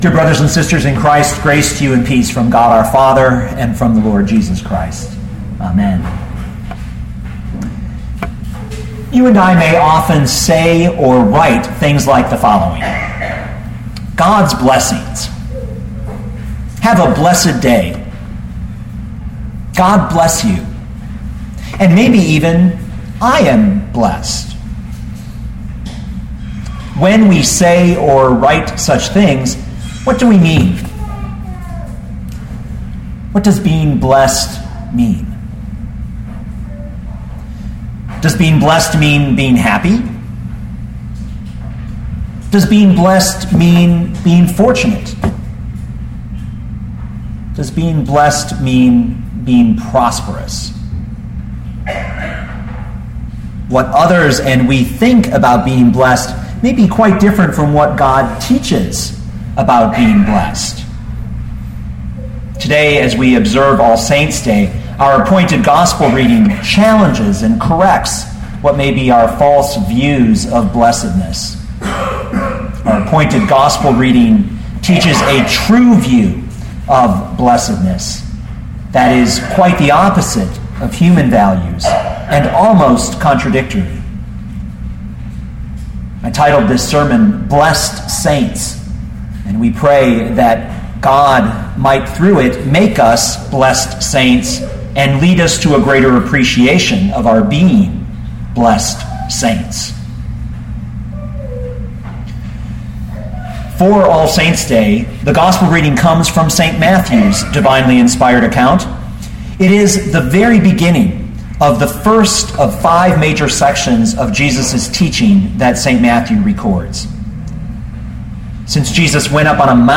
2012 Matthew 5:1-12 Listen to the sermon with the player below, or, download the audio.